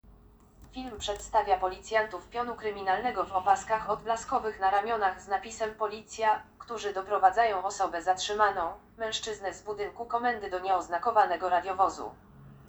Nagranie audio Autodeskrypcja_z_doprowadzenia_osoby_zarrzymanej.mp3